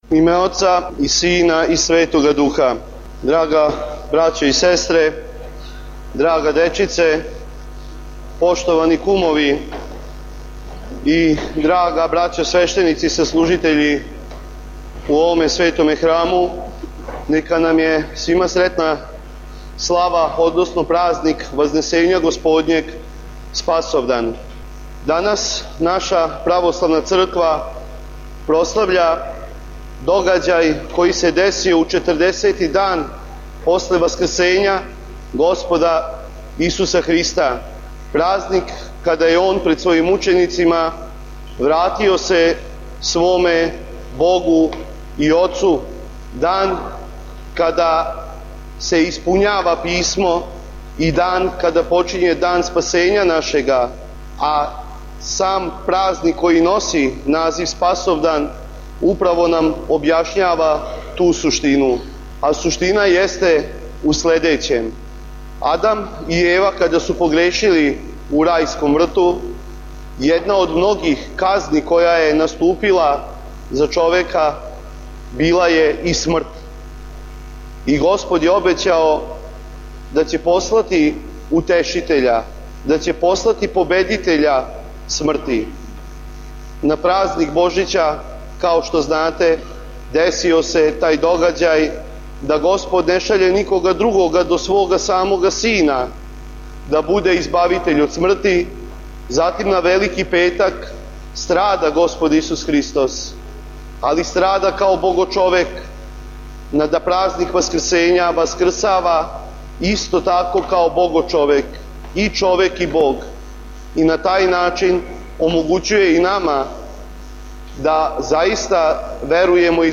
Прослава празника Вазнесења Господњег у Епархији бачкој
• Беседа